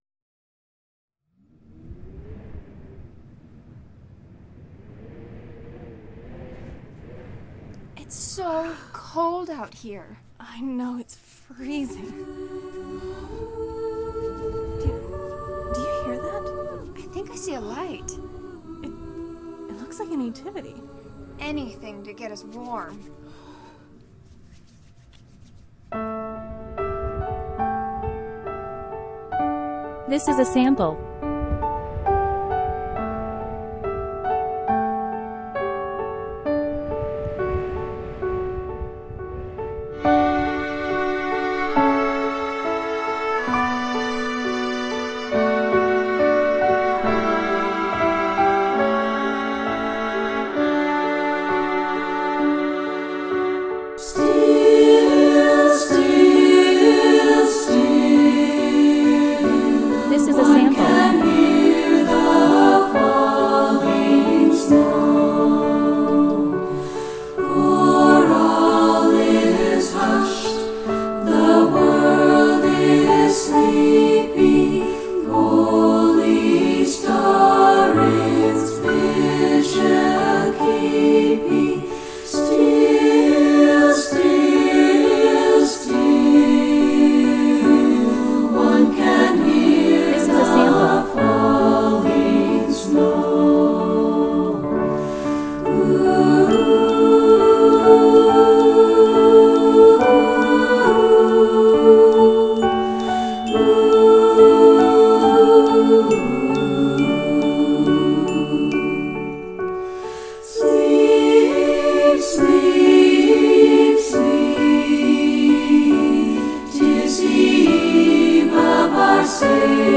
lullaby